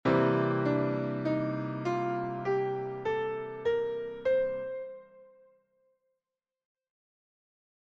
Blues Piano
Le mode dorien permet d’improviser dans un contexte mineur, avec une 7ème mineure :
on dispose alors des degrés 2 et 6, à considérer davantage comme notes de passage.